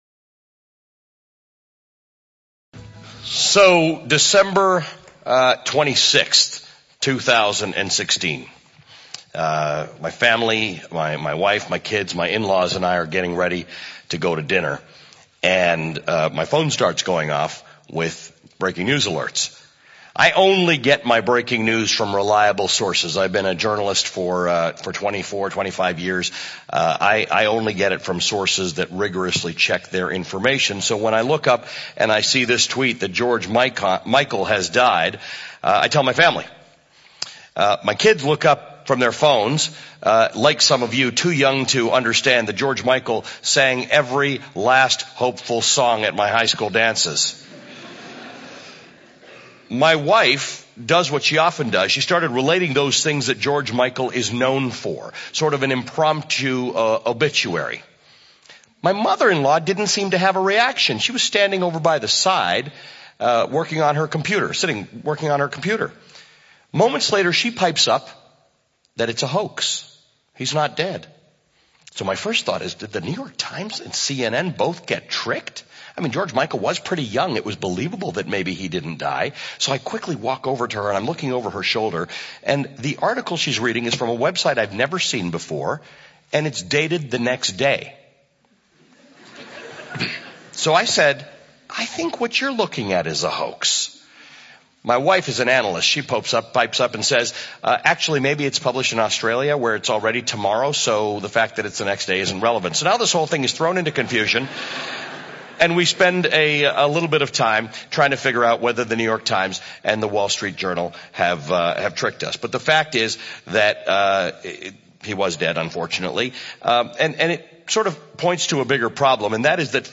Here is the full transcript of journalist Ali Velshi’s talk: How Fake News Grows in a Post-Fact World @ TEDxQueensU conference.